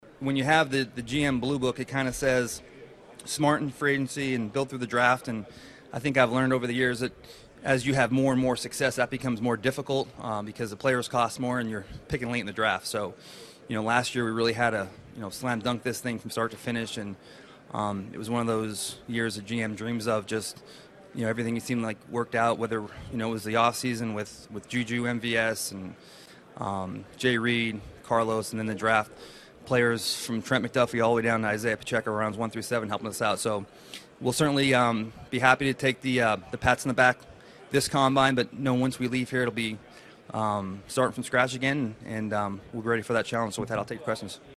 Chiefs GM Brett Veach at the NFL Combine in Indianapolis Brett Veach Chiefs plan this offseason.